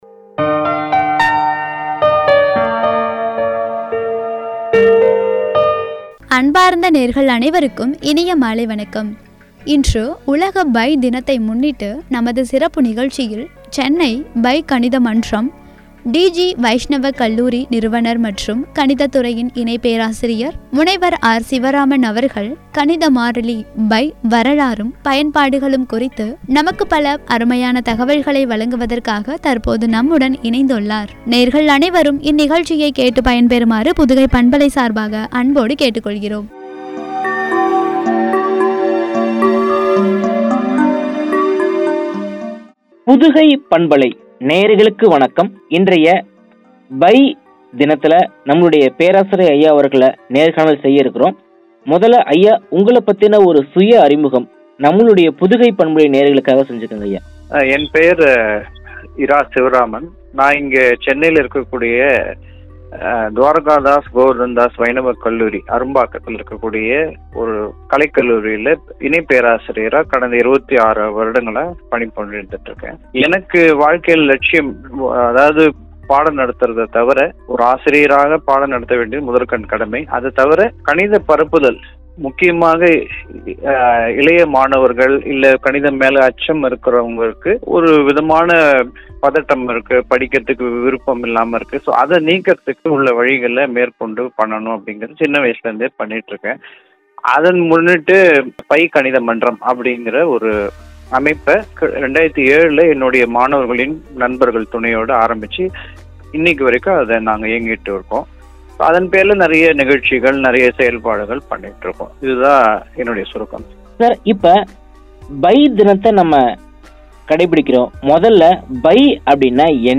பயன்பாடும் ” என்ற தலைப்பில் வழங்கிய உரையாடல்.